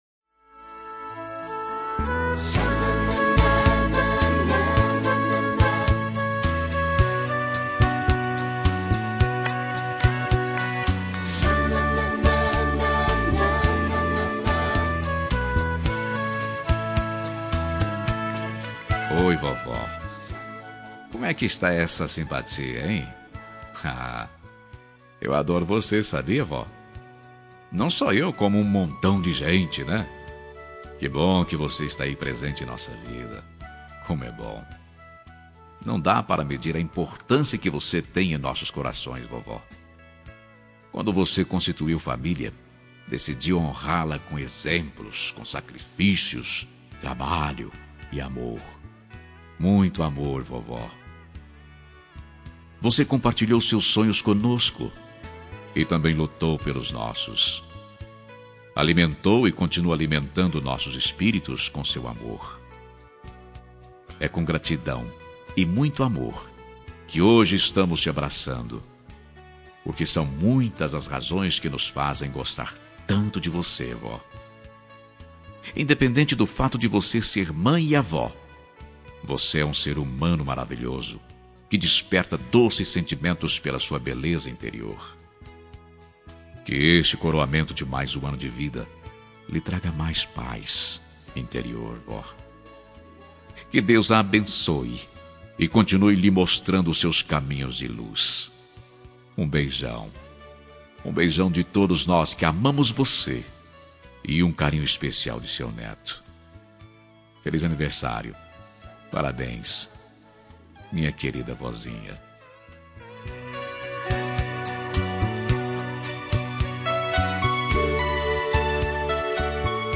Telemensagem Aniversário de Avó – Voz Masculina – Cód: 2072